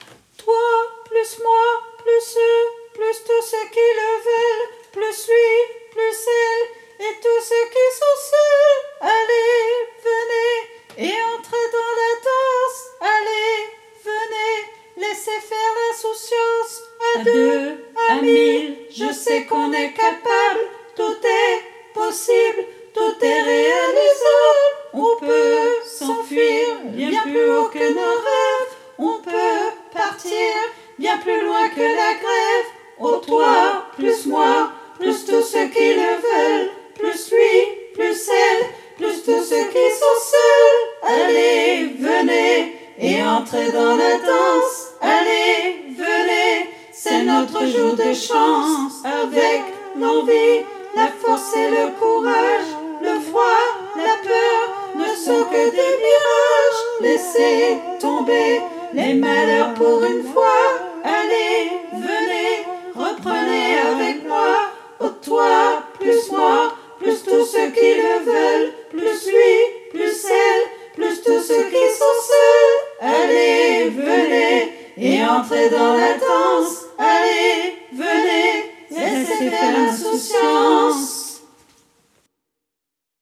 MP3 versions chantées
Tutti